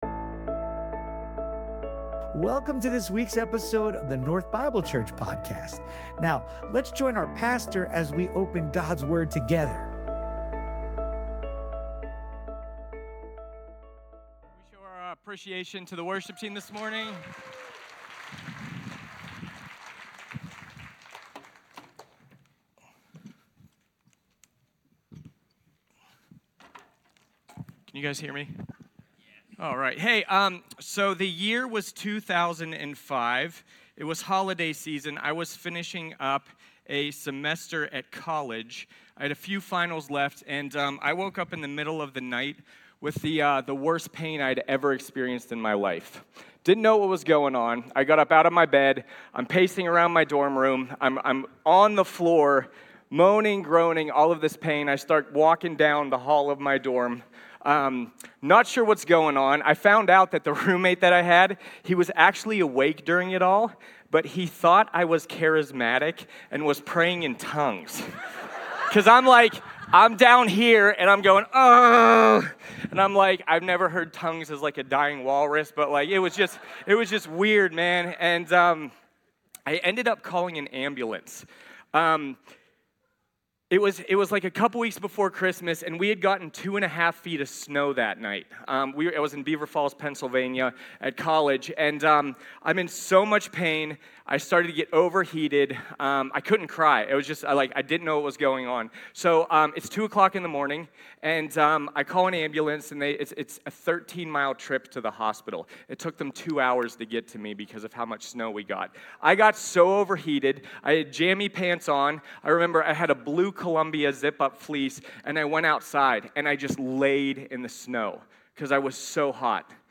Sermons from North Bible Church in Scottsdale, Arizona.